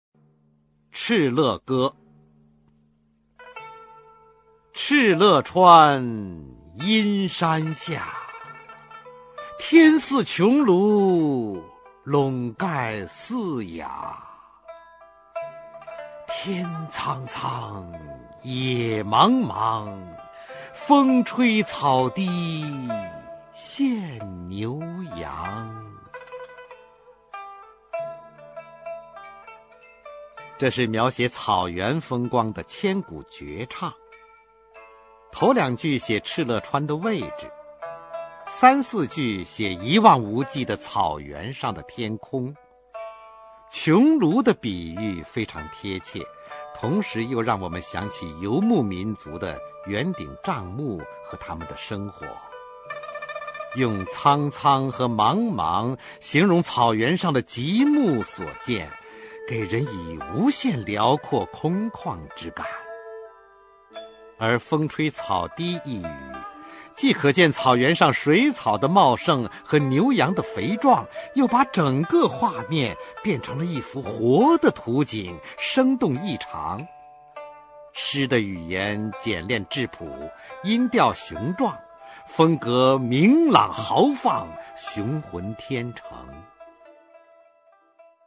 [魏晋诗词诵读]北朝民歌-敕勒歌（男） 古诗朗诵